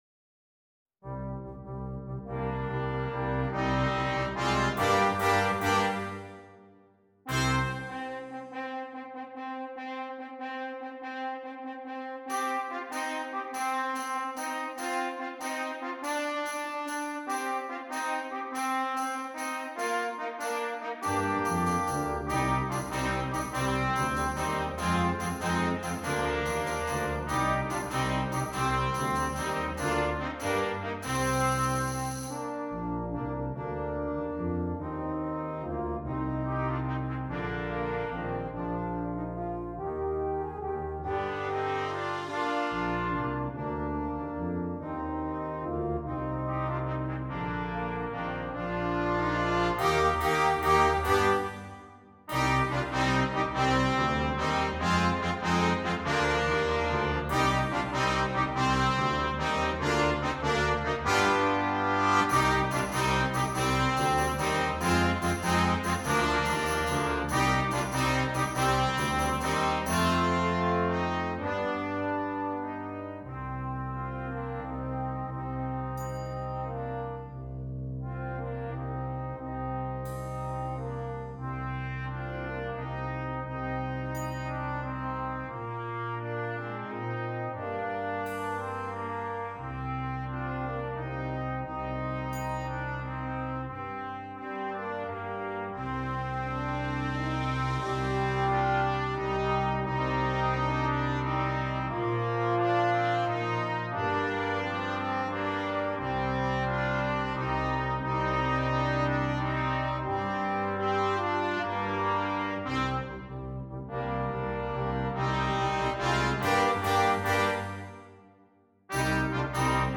Brass Quintet
heroic in nature
polyrhythmic texture